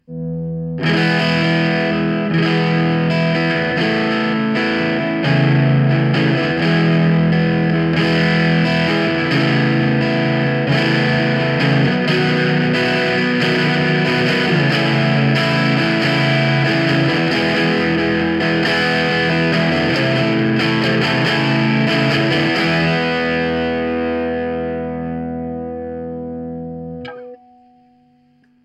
Dramatico.mp3